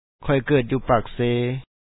khO3y ke3et ju#u pa3akse2e